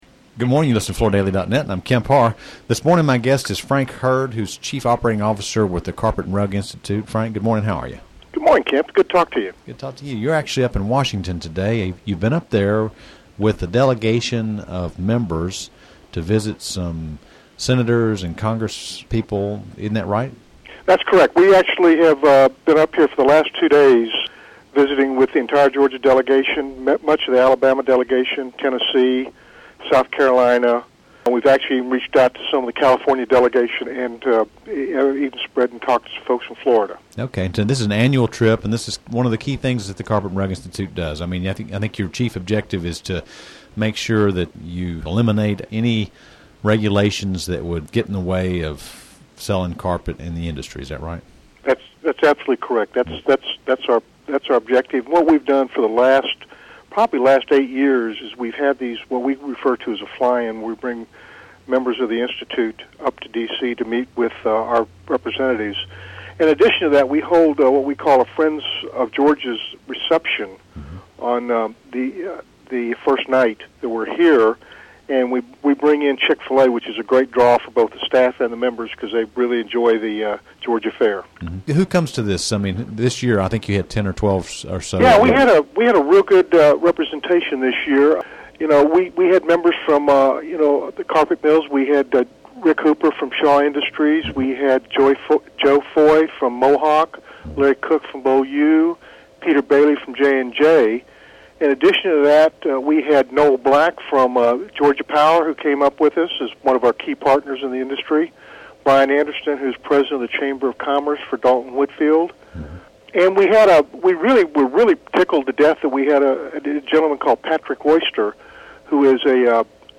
Listen to the interview to hear who attended these meetings and what was discussed. At the end of the interview we also discuss the progress that's been made to implement California AB 2398 on July 1st.